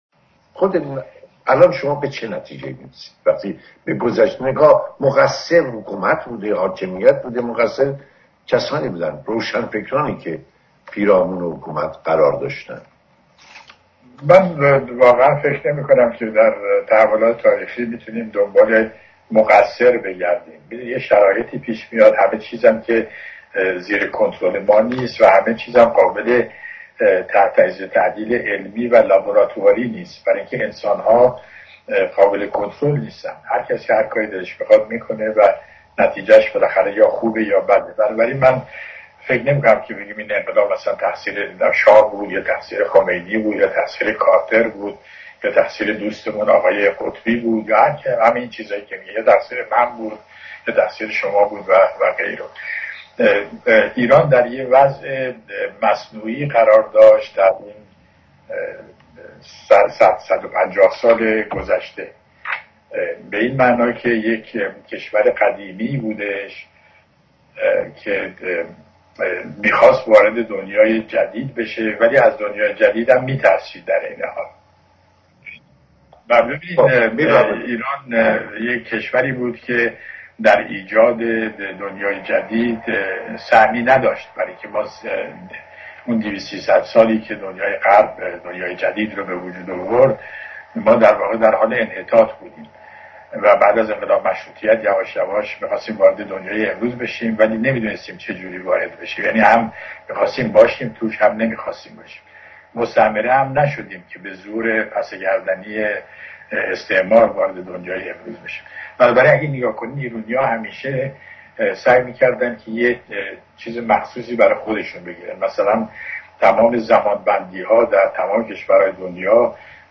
با آن صدای گرم و میزبانی حرفه‌ایشان